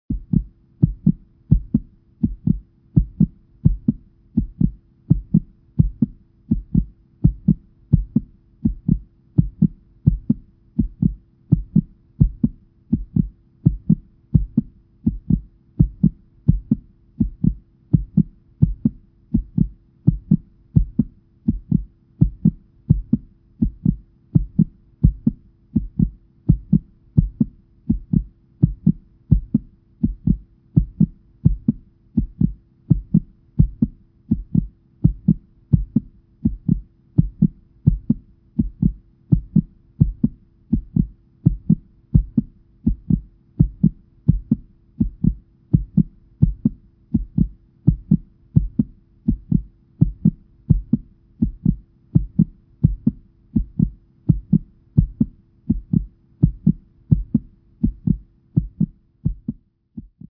جلوه های صوتی
دانلود صدای ضربان قلب انسان با ریتم تند و قوی از ساعد نیوز با لینک مستقیم و کیفیت بالا